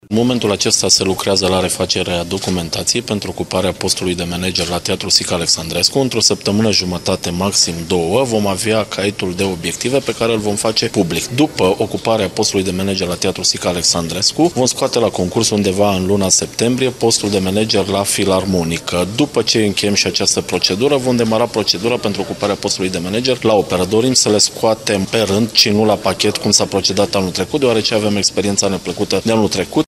Concursuri pentru ocuparea funcției de manager vor fi organizate și la Filarmonica Braşov, dar şi la Operă, spune viceprimarul Brașovului, Mihai Costel.